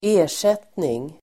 Uttal: [²'e:r_set:ning el. ²'ä:-]